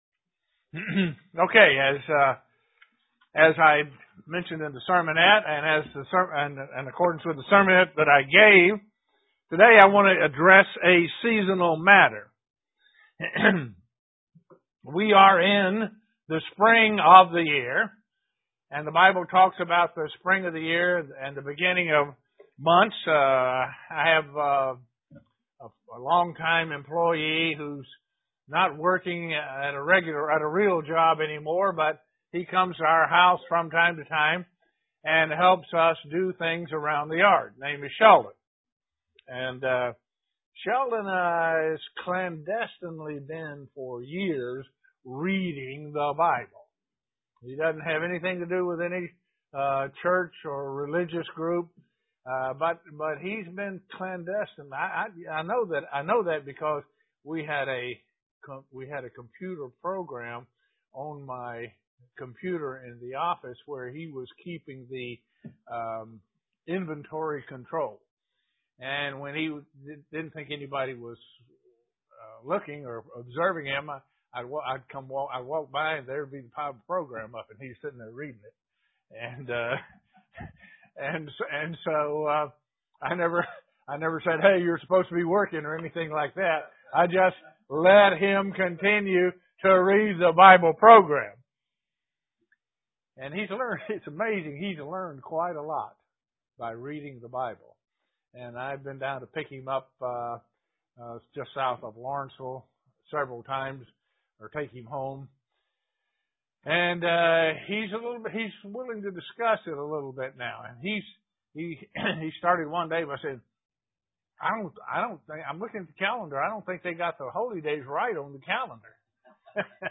Given in Elmira, NY
UCG Sermon Studying the bible?